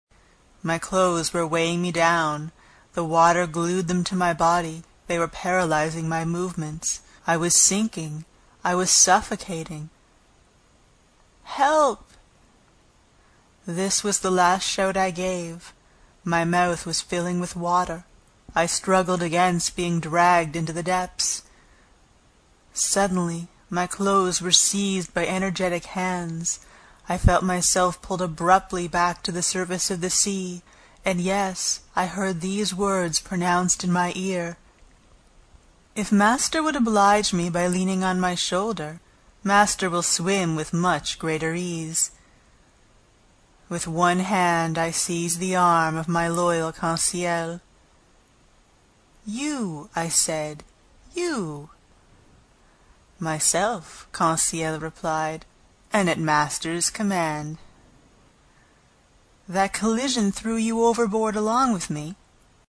英语听书《海底两万里》第79期 第7章 一种从未见过的鱼(2) 听力文件下载—在线英语听力室